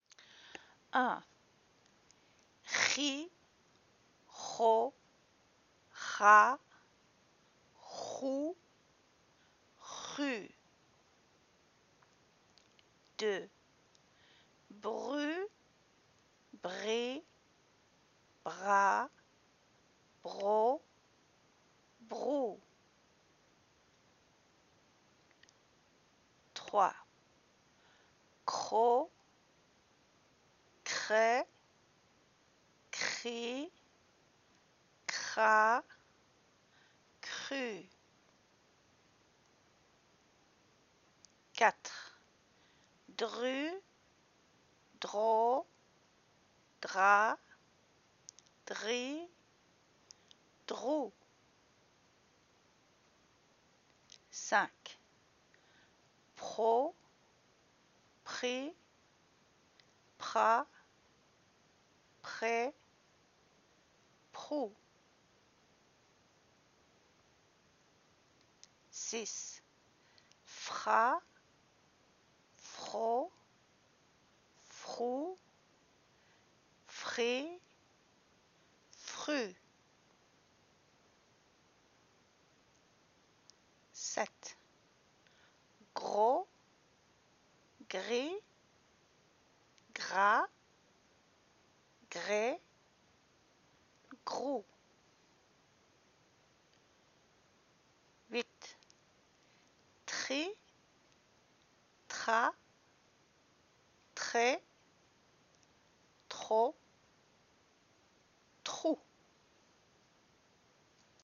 Guttural R
/ʁ/ (voiced uvular fricative)
Répétez (repeat) chaque combinaison avec le son ‘r’ (/ʁ/).